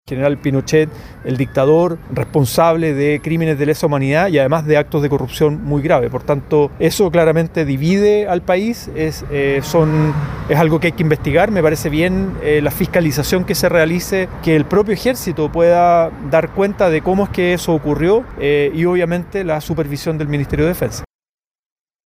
En una línea similar se expresó el senador del Frente Amplio, Juan Ignacio Latorre.